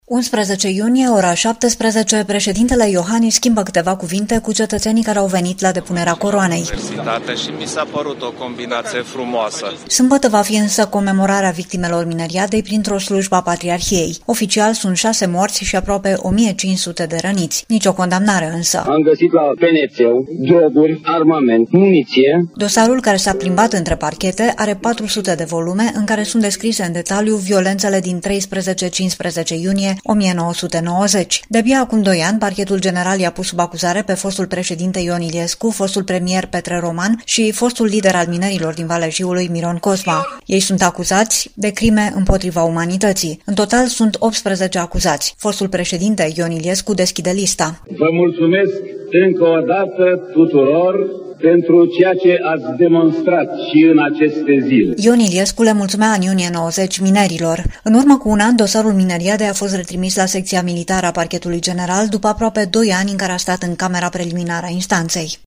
Ion Iliescu le mulțumea în iunie 1990 minerilor.